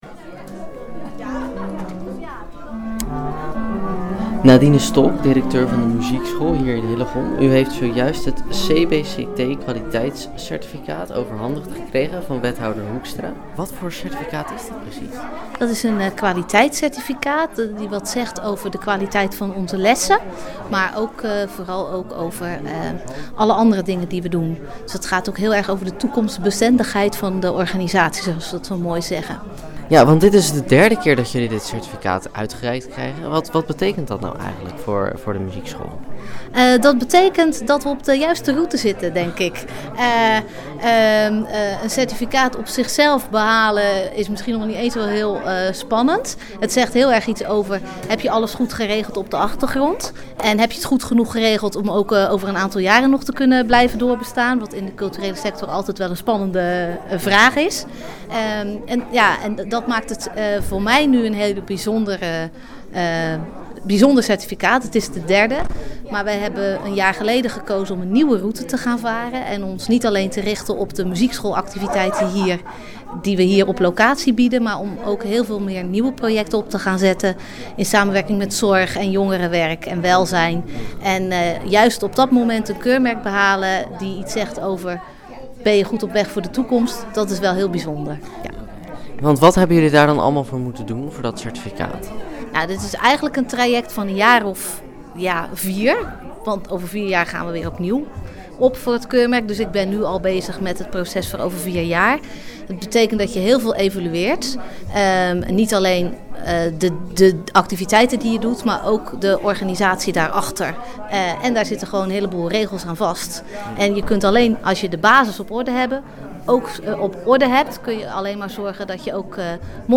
Het certificaat werd tijdens de feestelijke open dag overhandigd door wethouder van cultuur, Karin Hoekstra.